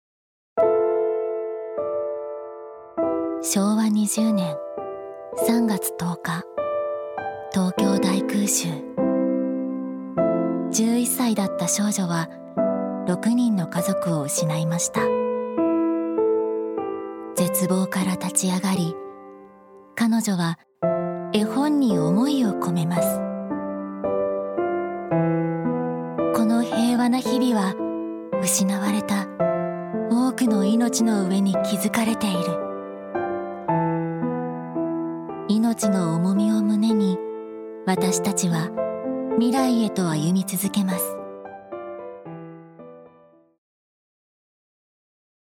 女性タレント
ナレーション８